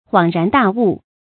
注音：ㄏㄨㄤˇ ㄖㄢˊ ㄉㄚˋ ㄨˋ
恍然大悟的讀法